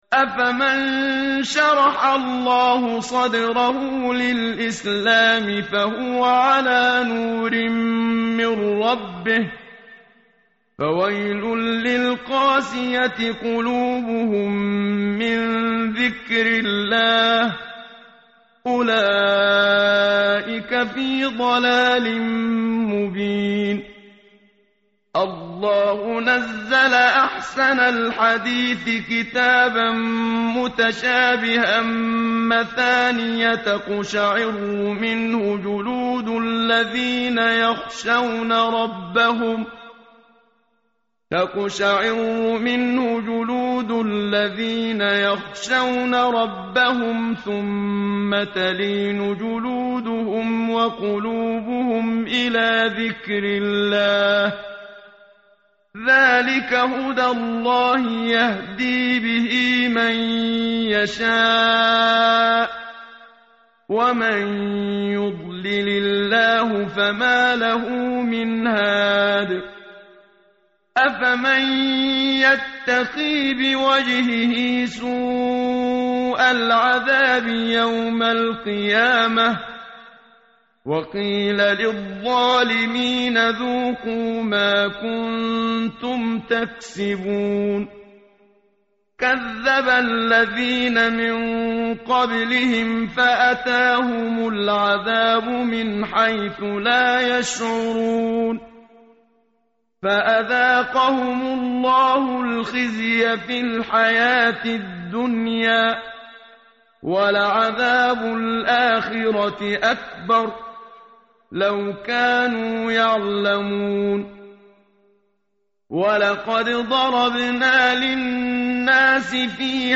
متن قرآن همراه باتلاوت قرآن و ترجمه
tartil_menshavi_page_461.mp3